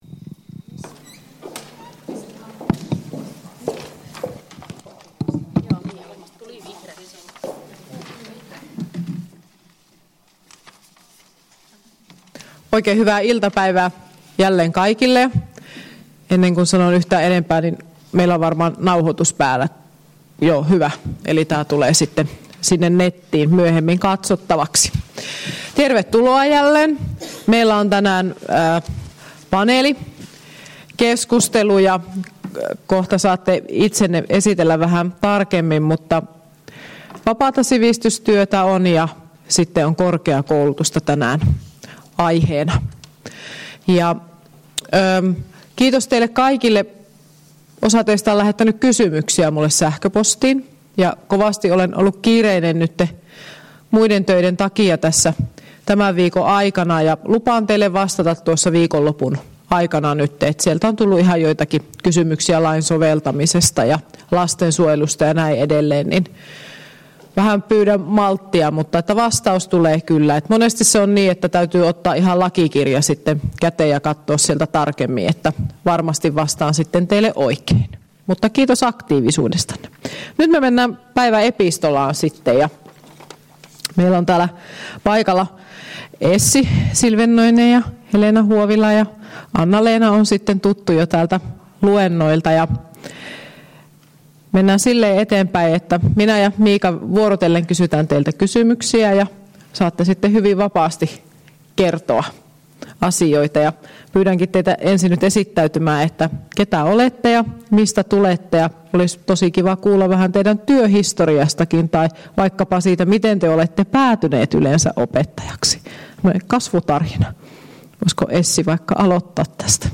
Luento 12.2.2015 — Moniviestin